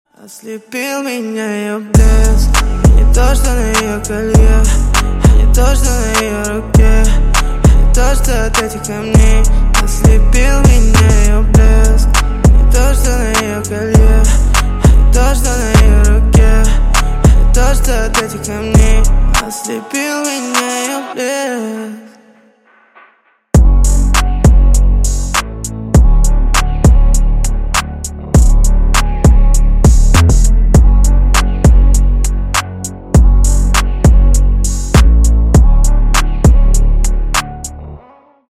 Громкие Рингтоны С Басами
Поп Рингтоны